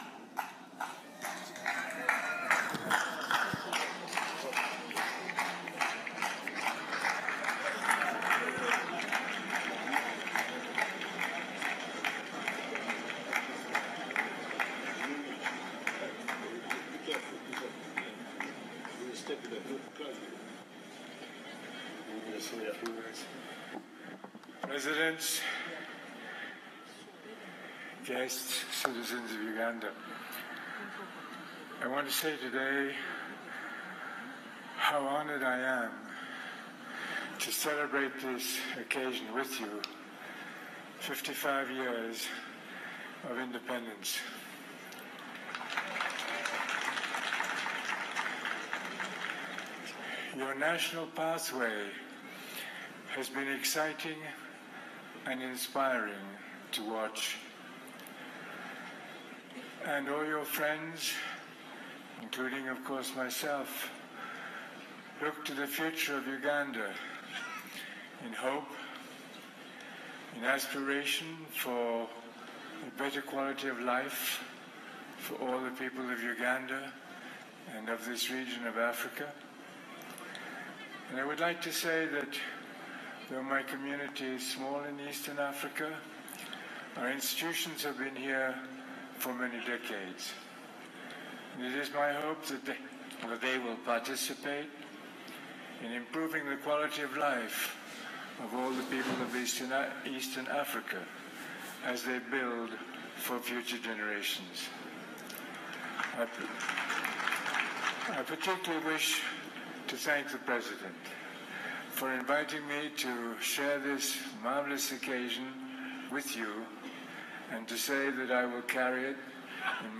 Aga Khan at Uganda’s 55th Uhuru Celebrations
AUDIO OF REMARKS BY HIS HIGHNESS THE AGA KHAN UPON RECEIVING UGANDA’S HIGHEST HONOUR
aga-khan-remarks.m4a